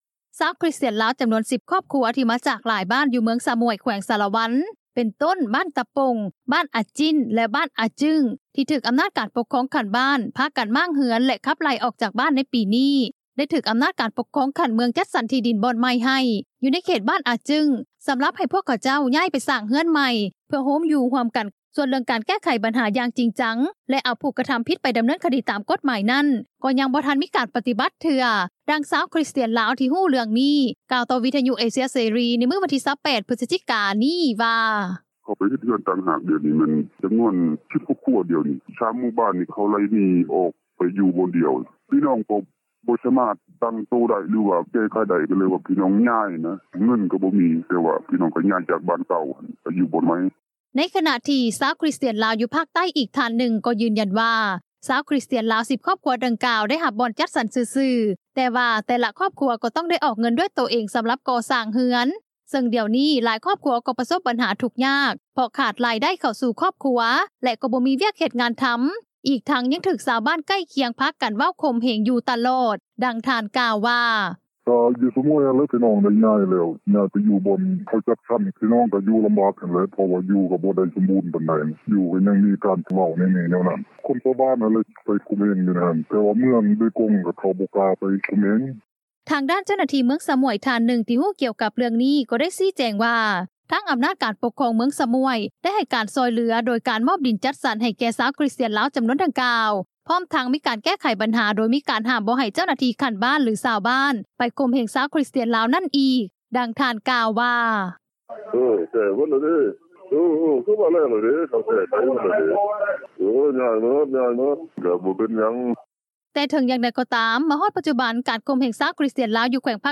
ດັ່ງຊາວຄຣິສຕຽນລາວ ທີ່ຮູ້ເຣື່ອງນີ້ ກ່າວຕໍ່ວິທຍຸເອເຊັຽເສຣີ ໃນມື້ວັນທີ 28 ພຶສຈິກາ ນີ້ວ່າ:
ດັ່ງຊາວຄຣິສຕຽນລາວ ຢູ່ແຂວງພາກໃຕ້ ທ່ານນຶ່ງກ່າວວ່າ: